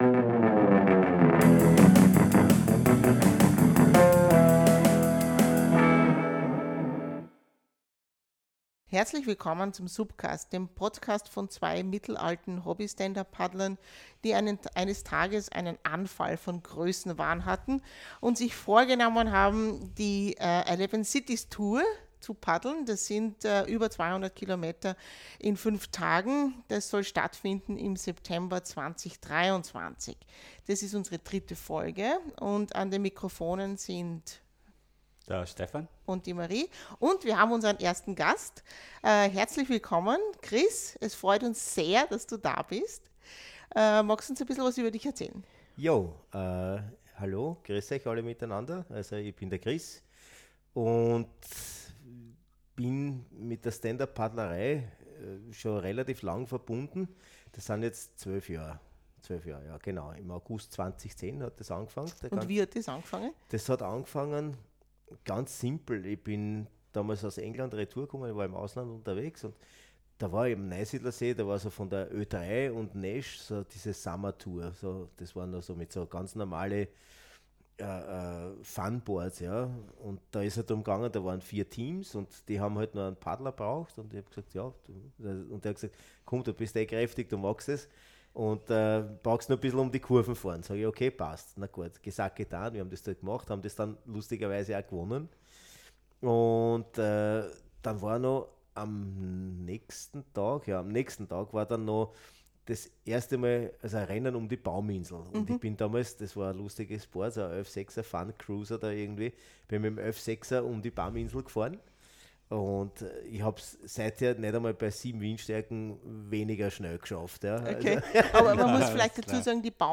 Da wir in ostösterreichischem Dialekt sprechen, hier ein kleines Dialekt-Wörterbuch: